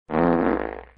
Sound Buttons: Sound Buttons View : Fart 1
Fart-sound-effect-1.mp3